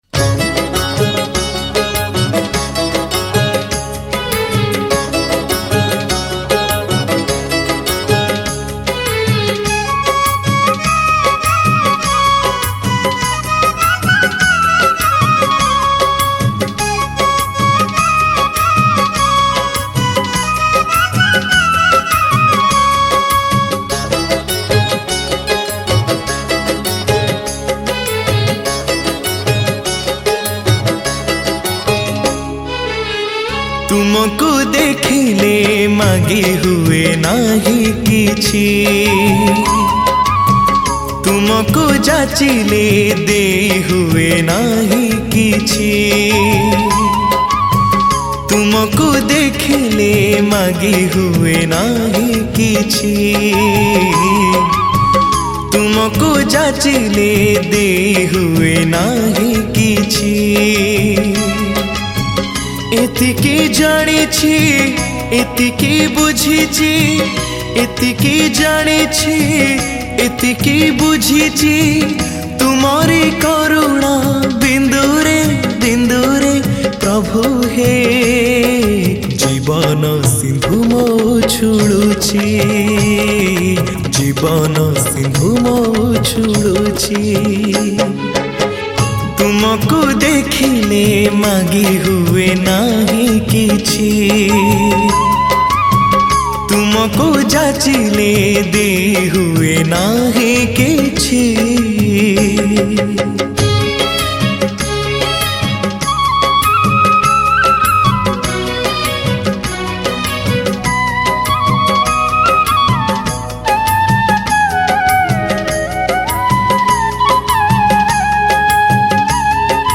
Odia Bhakti Song